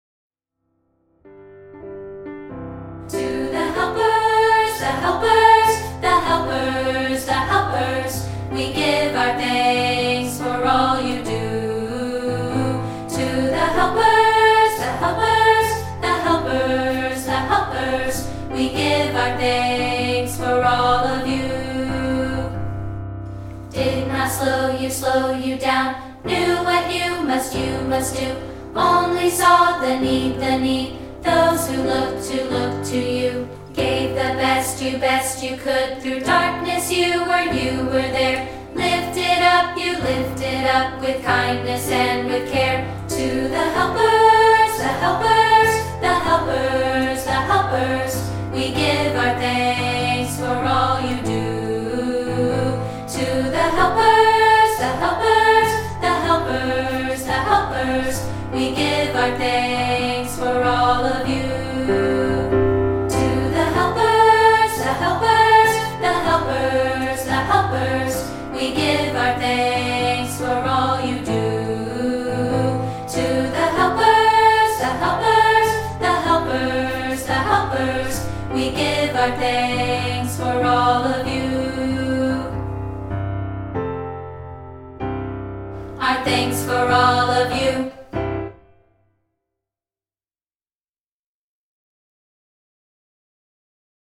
including this rehearsal track of part 2, isolated.